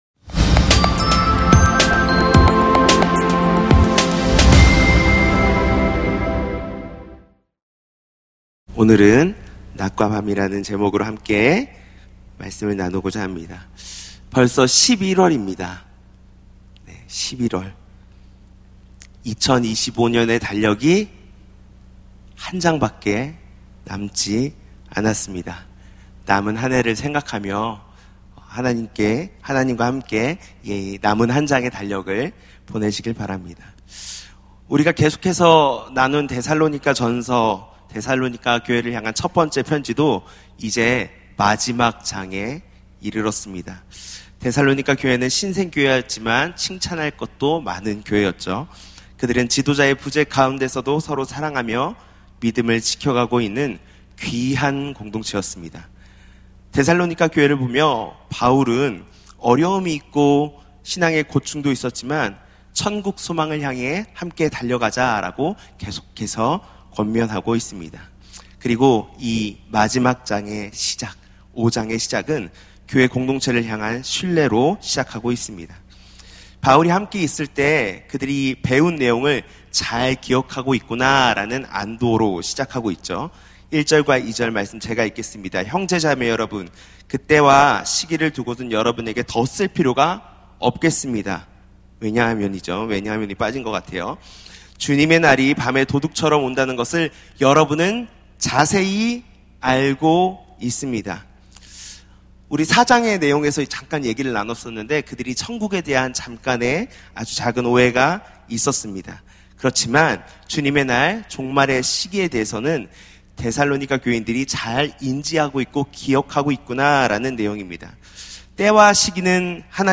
낮과 밤 > 예배와 말씀